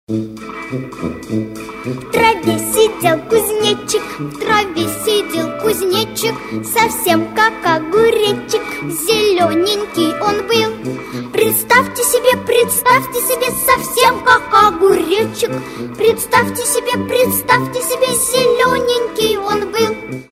Песня из мультфильма